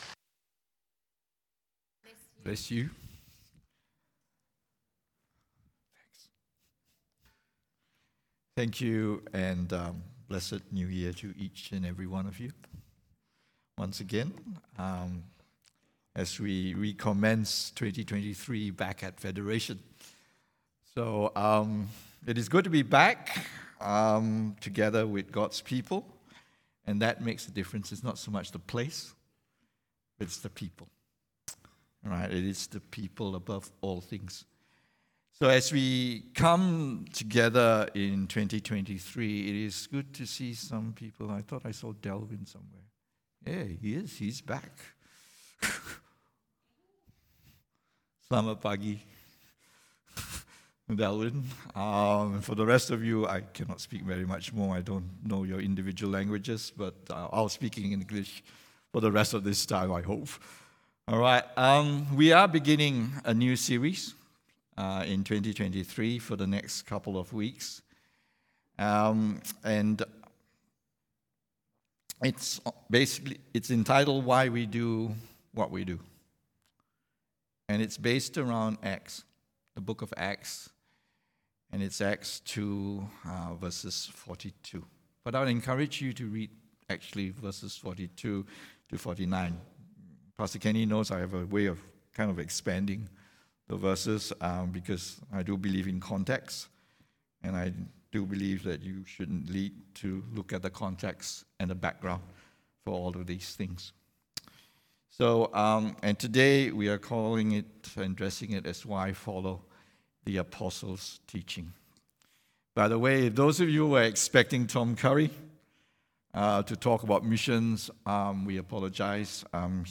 English Worship Service - 8th January 2023
Sermon Notes